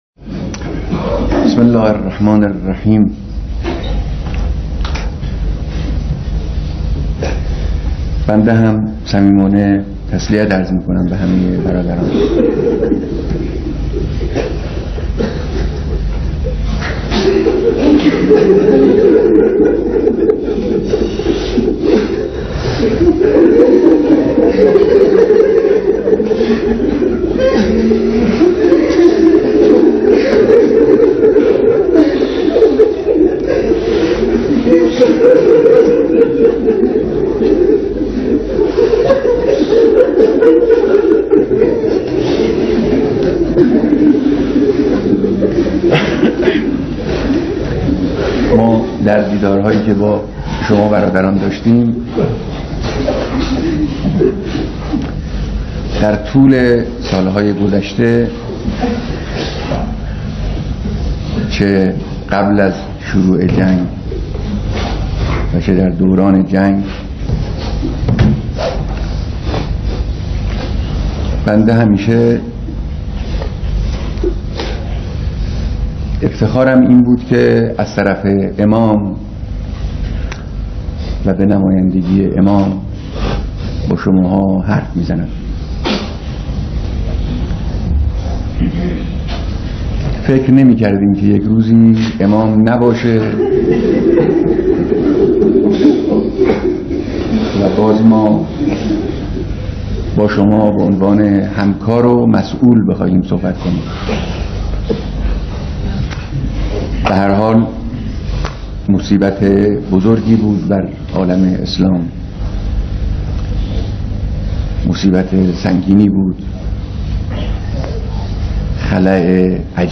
بیانات رهبر انقلاب در مراسم بیعت فرماندهان و مسؤولان سپاه پاسداران